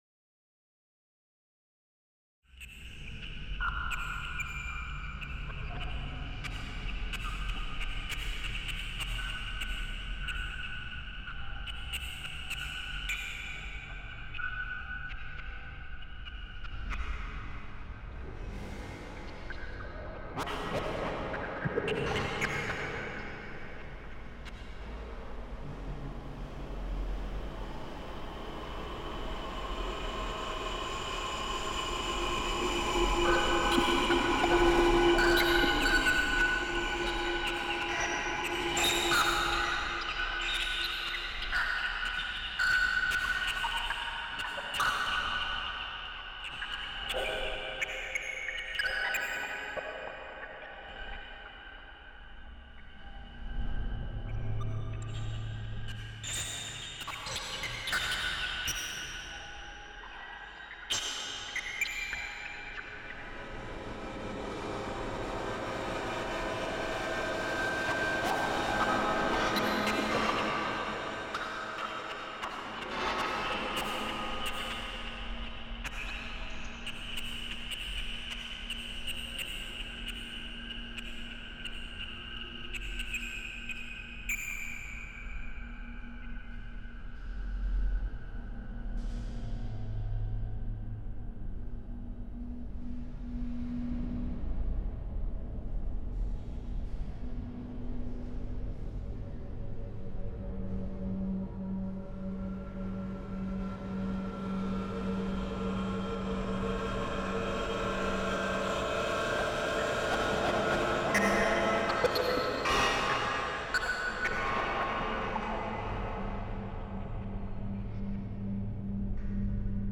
Feedback tussen microfoons, computer en luidsprekers zorgt voor het ontstaan van ‘loops’ die zich voortdurend transformeren. Door de digitale opsplitsing van het klankspectrum in nauwe banden van amplitude en frequentie, gedragen deze feedbackloops zich erg onstabiel en worden ze gevoelig voor externe invloeden.
audio fragment (recorded at ccnoa, Brussels, 10/2004)